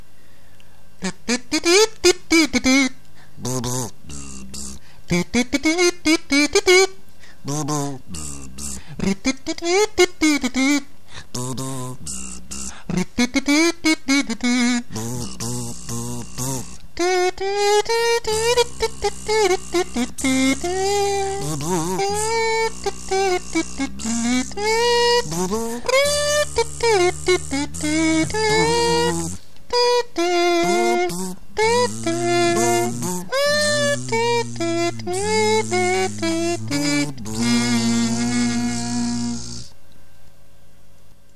Essaim d'abeilles
abeille_essaim.mp3